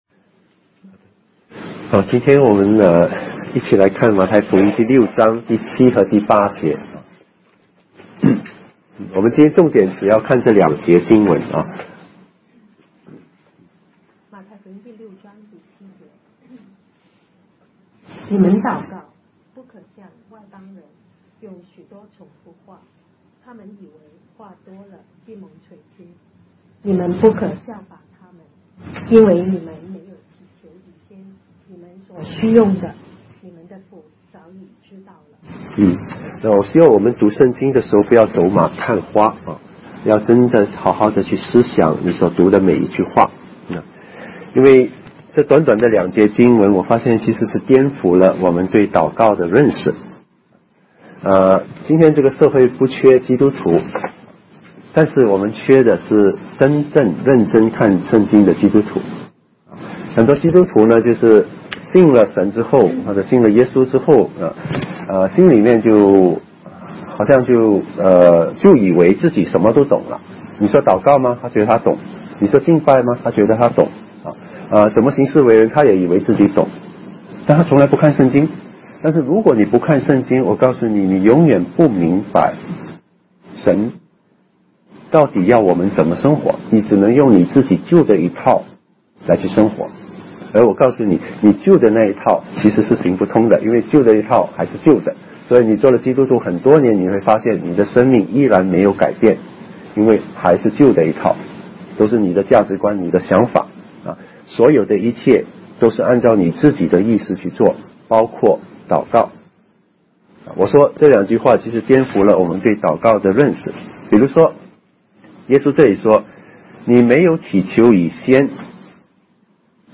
信息